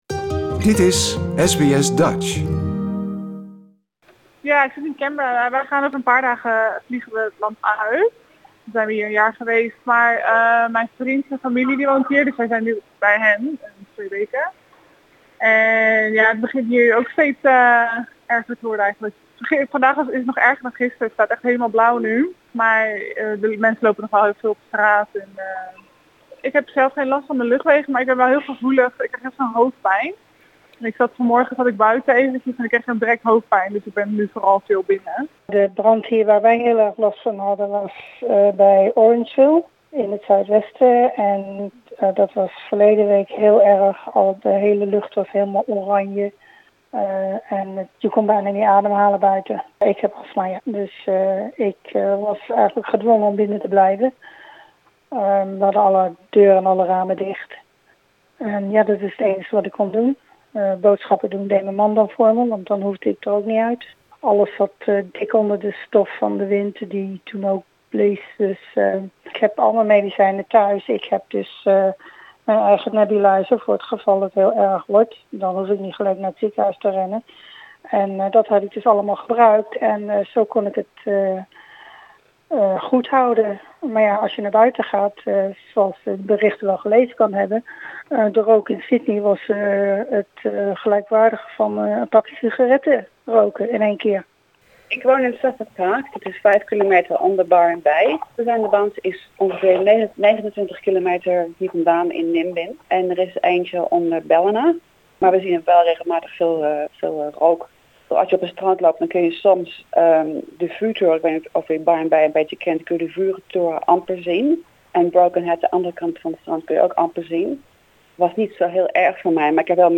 SBS Dutch sprak met enkele Nederlanders die te kampen hebben met rookoverlast en bemerkte onrust.